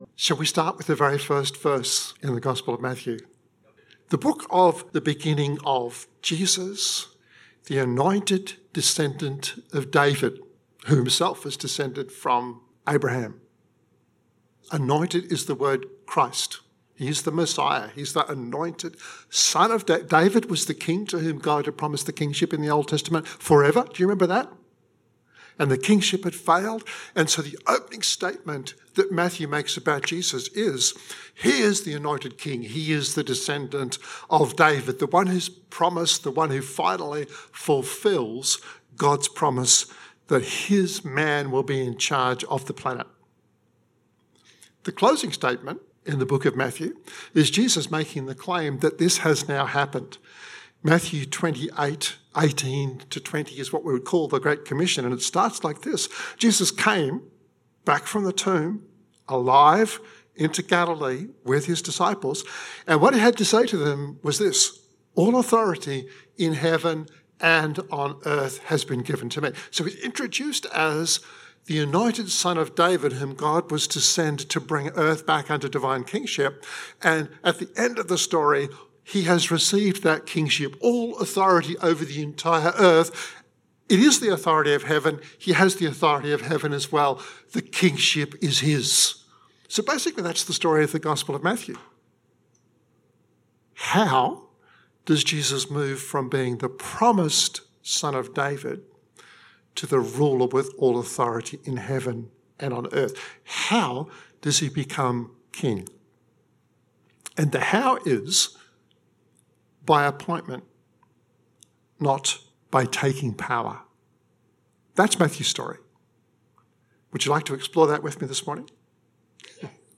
We follow Matthew’s main message in this 27-minute podcast , recorded at Riverview Joondalup 2022-03-06.